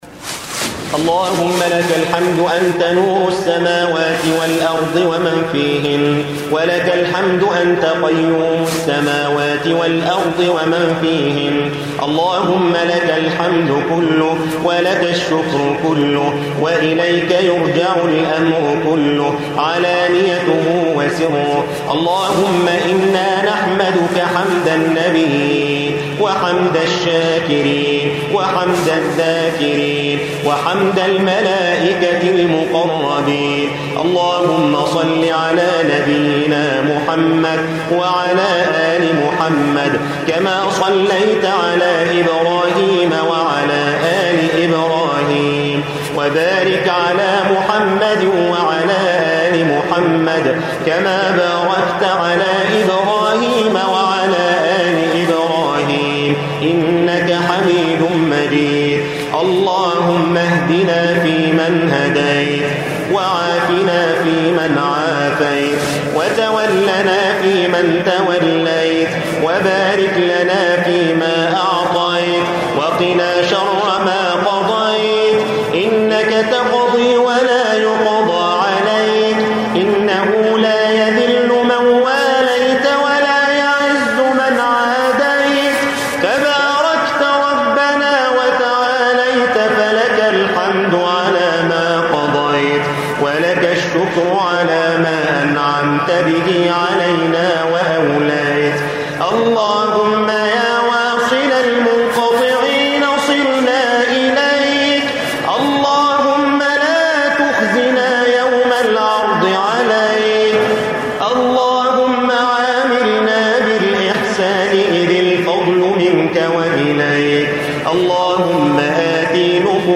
دعاء تراويح رمضان 1435